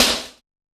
SNARE CUFF.wav